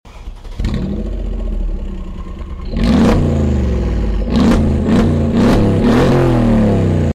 Check out the exhaust sound sound effects free download
Check out the exhaust sound of the Mercedes-AMG GLE 53!